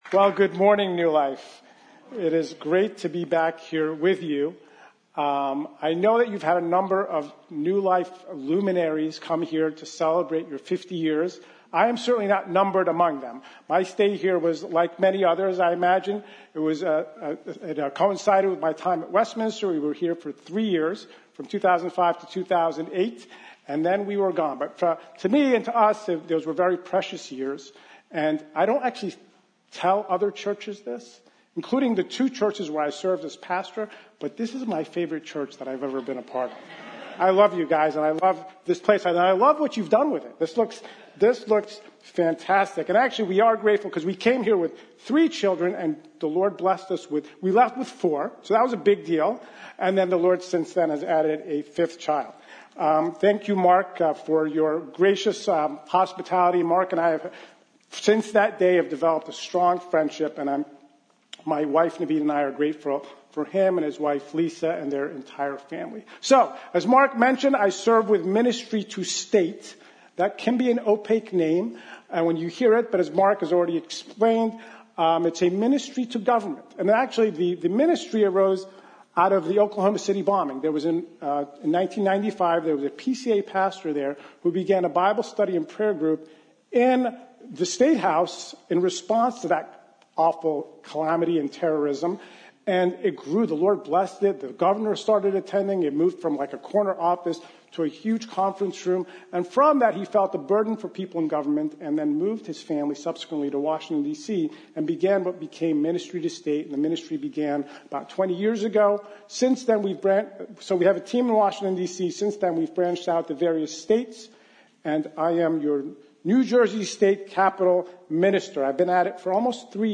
From Series: "50th Anniversary Sermons"